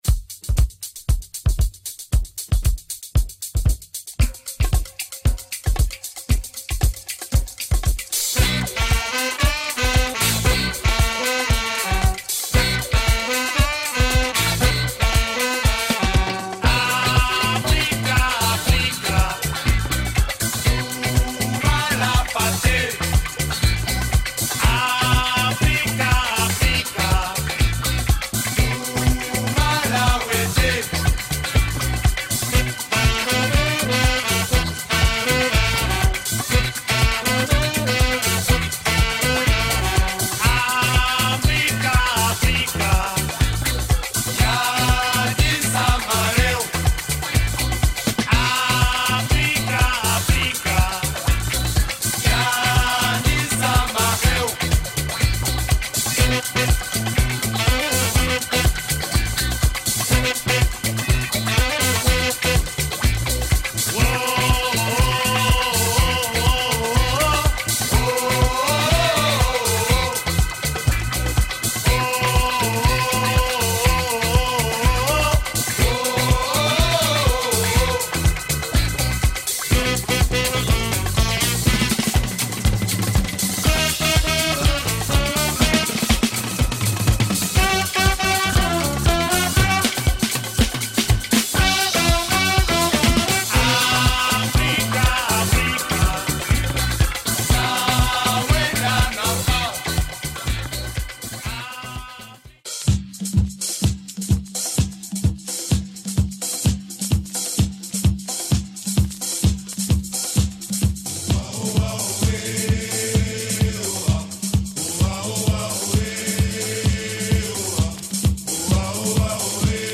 Their first album, including afro disco and funk tunes.